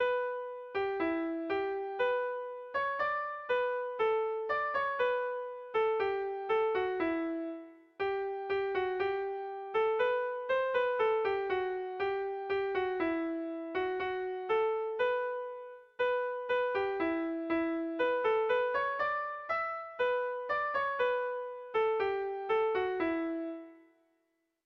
Kontakizunezkoa
ABDE